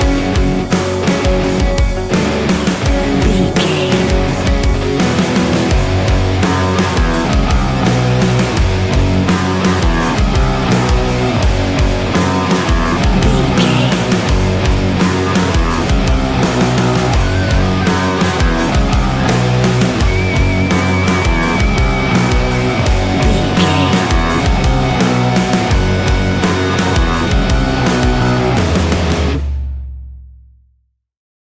Ionian/Major
F♯
hard rock
guitars
heavy metal
instrumentals